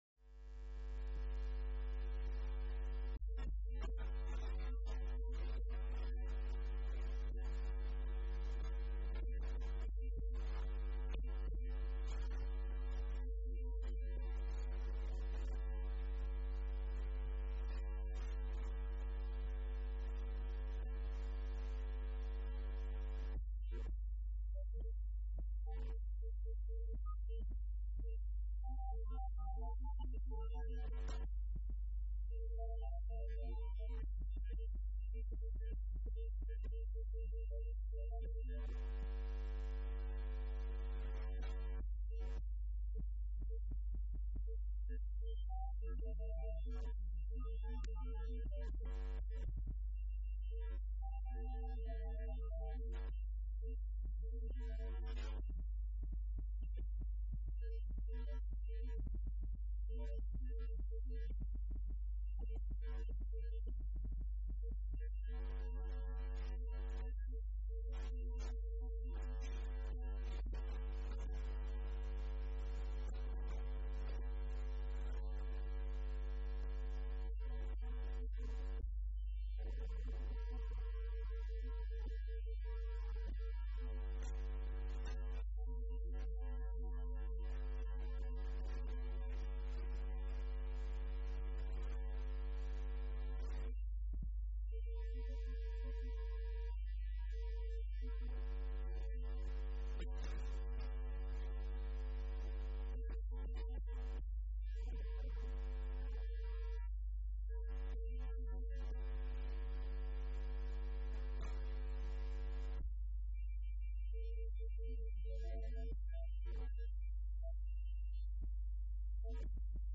contrafagote,
teste_contrafagote.mp3